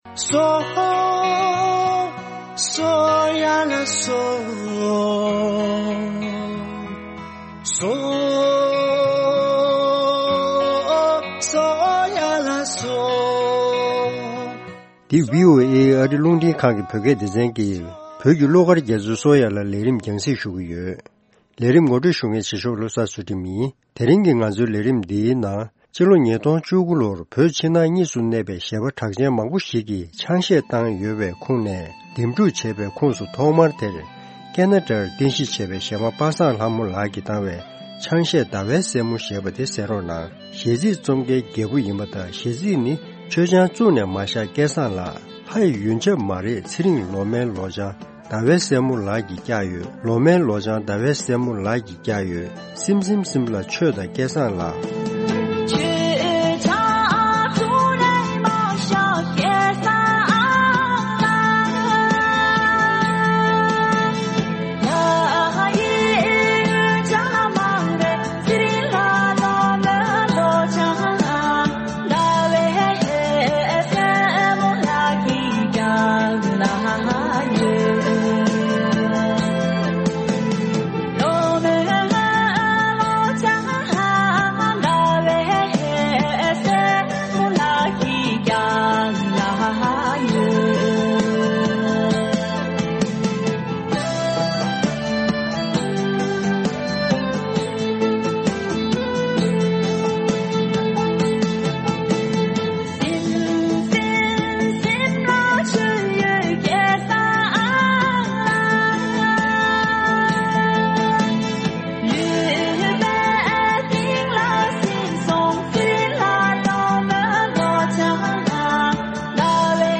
Tibetan wine-drinking song